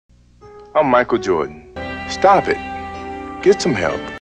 stop-it.mp3